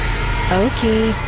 okie cyn Meme Sound Effect